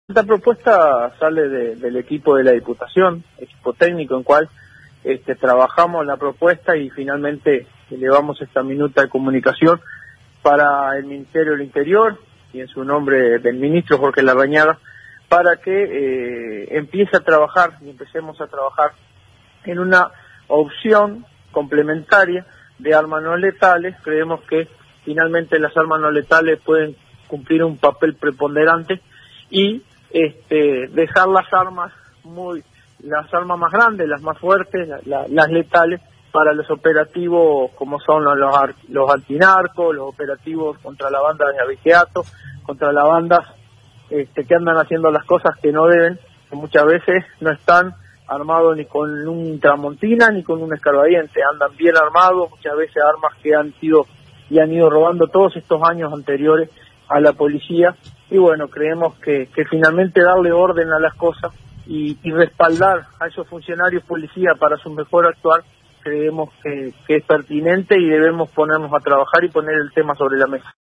El diputado por el sector Ciudadanos del Partido Colorado Juan Carlos Moreno en diálogo con 970 Noticias se refirió a la propuesta enviada hacia el Ministerio del Interior sobre el uso de armas no letales.